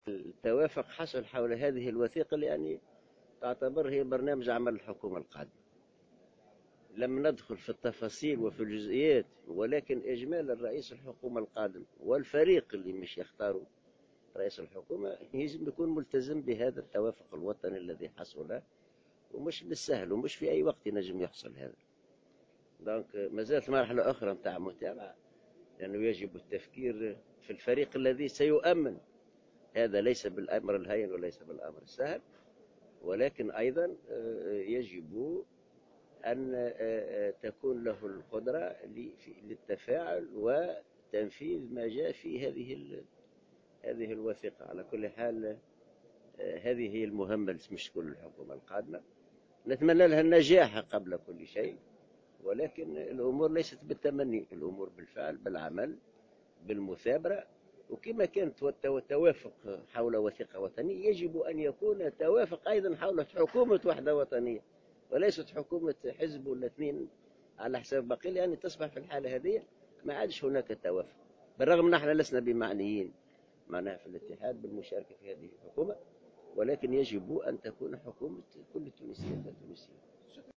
Le secrétaire général de l'Union générale tunisienne du travail (UGTT), Houcine Abassi a affirmé ce mercredi 13 juillet 2016, en marge de la cérémonie organisée à l'occasion de la signature de "l'accord de Carthage", que le prochain gouvernement est appelé à respecter l'accord définissant les priorités du gouvernement d'union nationale.